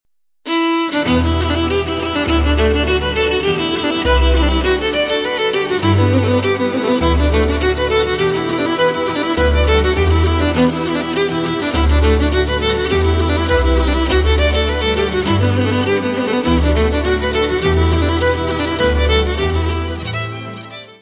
: stereo; 12 cm
Music Category/Genre:  World and Traditional Music